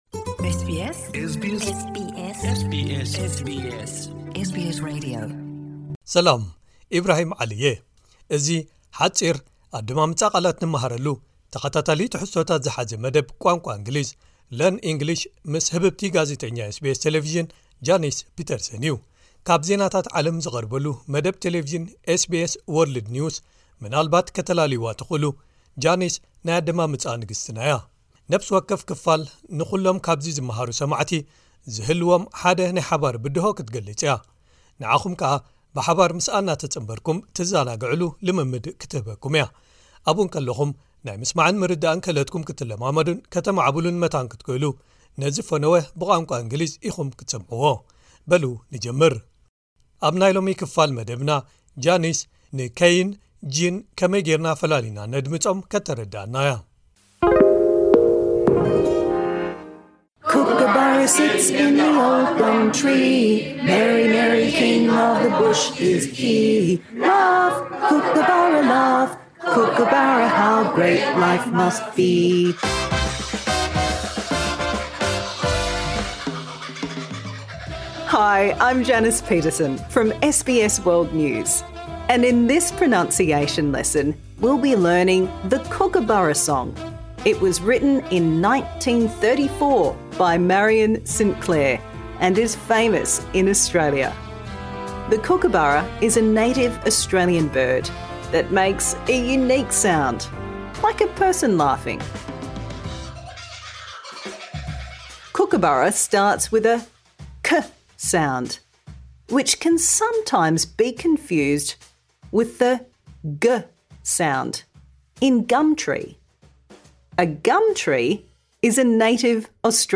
Improve your pronunciation | Season 2